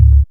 SONAR WAVE.wav